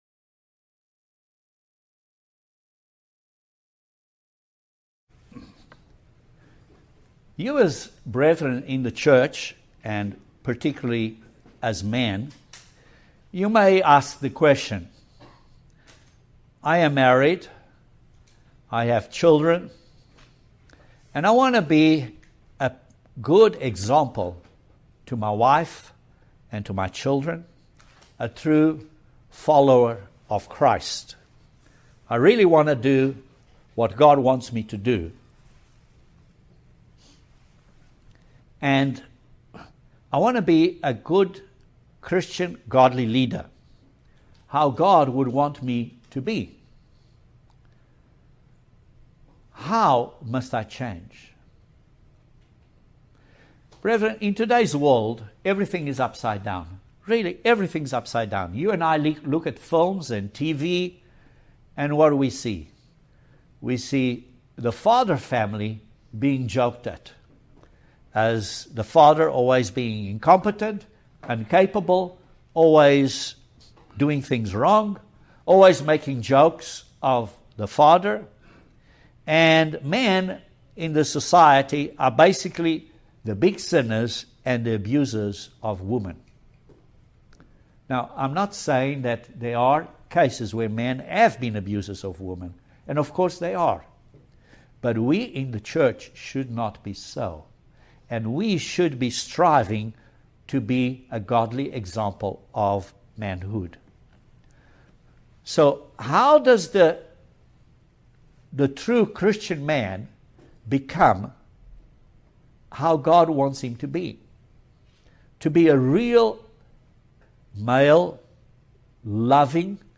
Join us for this excellent video sermon on the characteristics of an Godly husband according to God's word.